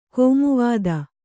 ♪ kōmuvvāda